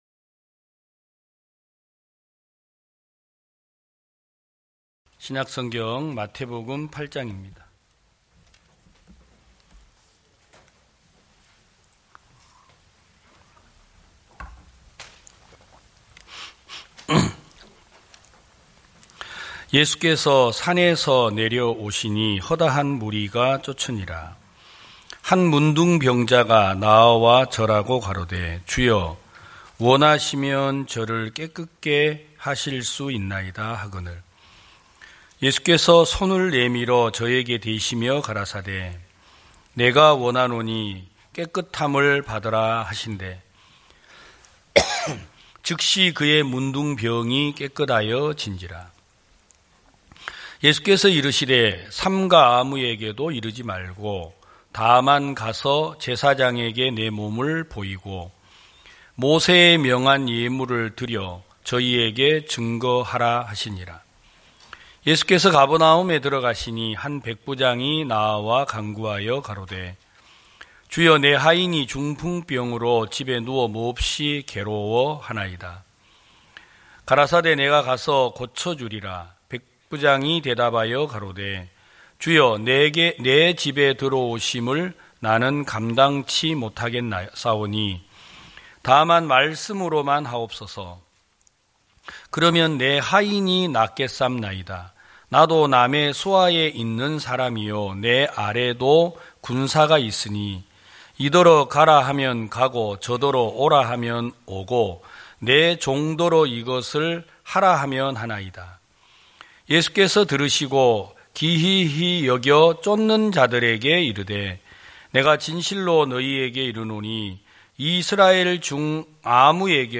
2021년 12월 05일 기쁜소식부산대연교회 주일오전예배
성도들이 모두 교회에 모여 말씀을 듣는 주일 예배의 설교는, 한 주간 우리 마음을 채웠던 생각을 내려두고 하나님의 말씀으로 가득 채우는 시간입니다.